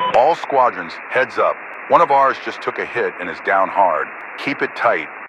Radio-commandFriendlyDown3.ogg